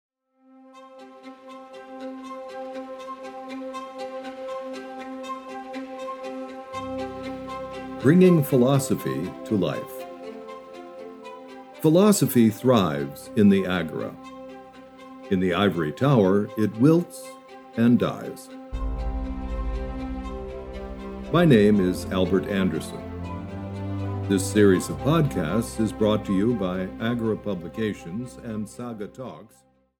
The Art of Democracy (EN) audiokniha
Ukázka z knihy